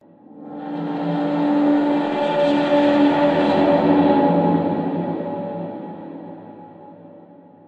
Звуки страшные, жуткие
Жутко страшно